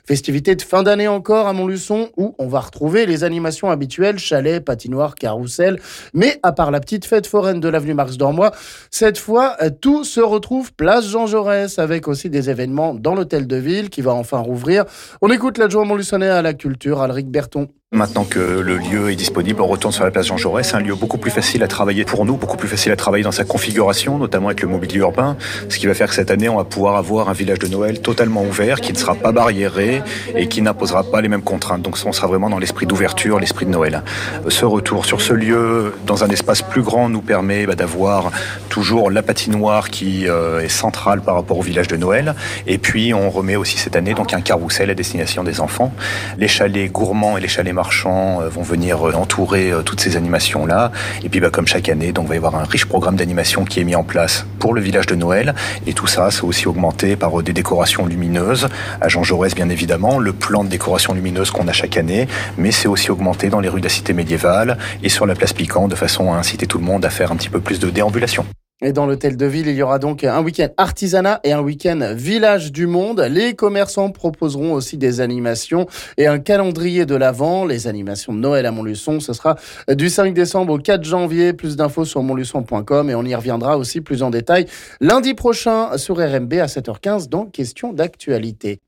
On en parle avec l'adjoint à la cuture Alric Berton...